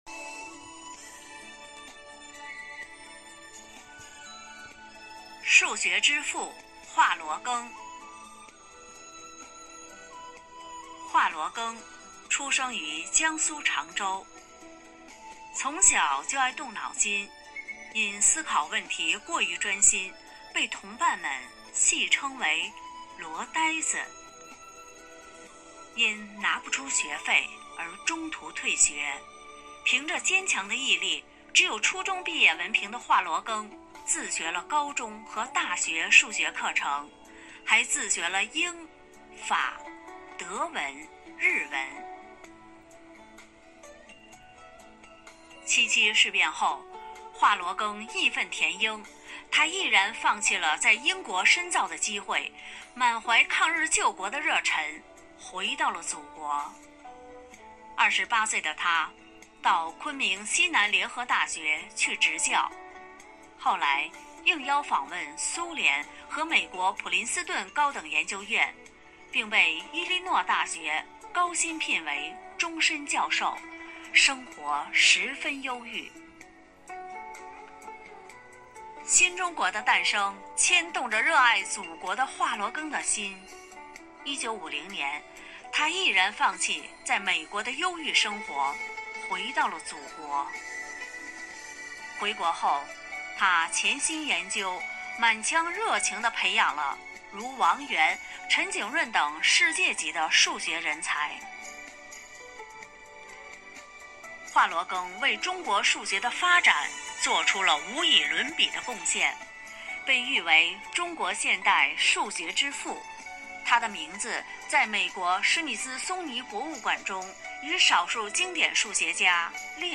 五一劳动节来临之际，为致敬最美劳动者，4月28日，生活好课堂幸福志愿者魅力之声朗读服务（支）队举办“致敬最美劳动者 一一我心中的故事”云朗诵会。